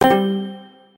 match-confirm.ogg